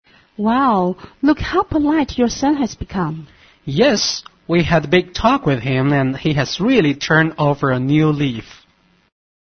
dialogue001.mp3